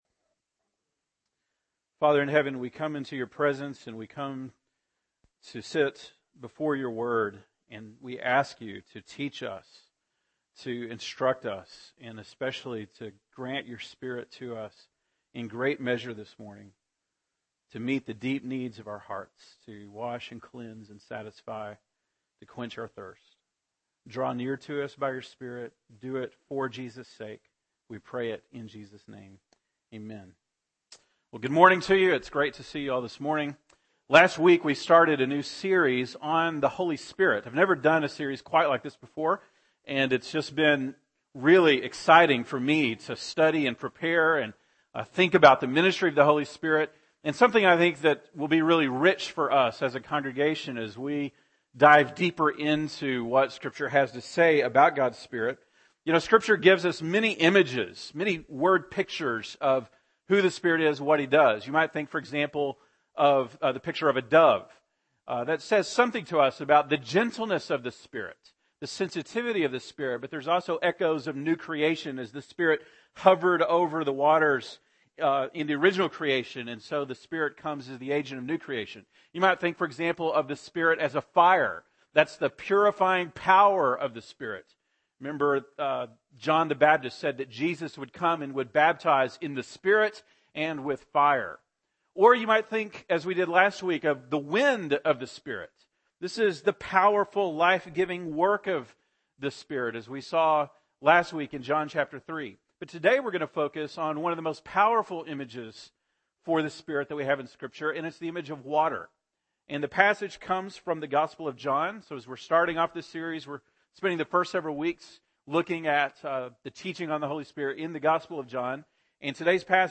October 20, 2013 (Sunday Morning)